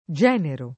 genero [ J$ nero ] s. m.